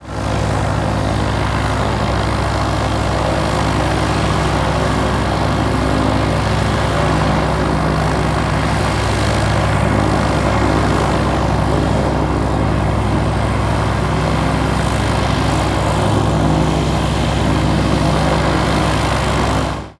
Звуки пропеллера
Шум пропеллера самолета